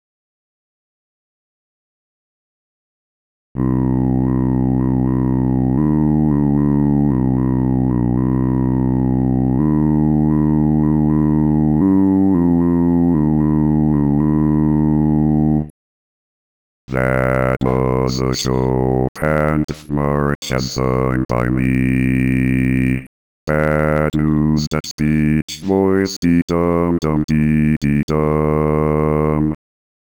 Some of the voices actually "sing" - or attempt to put a string of words in musical format.
Now here are Apple's musical voices and the song associated with them (First):
Bad News: A Chopin Death March